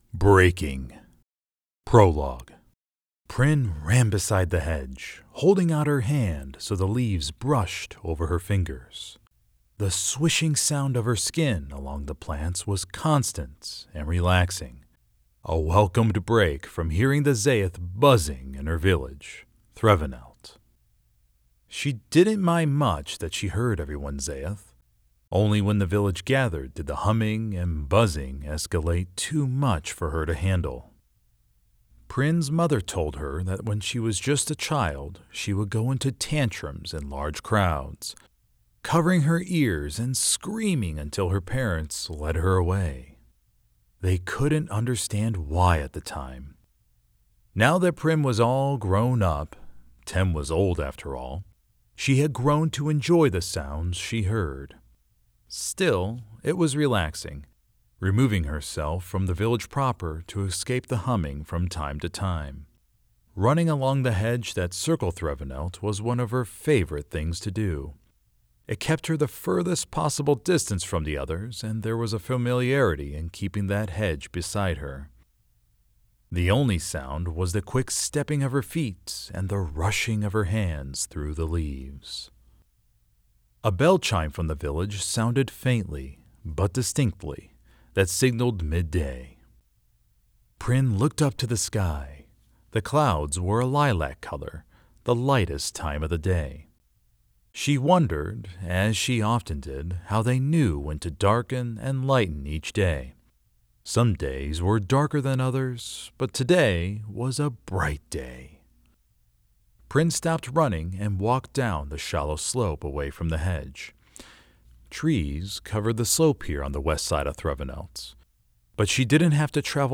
Breaking - Prologue - Audiobook Sample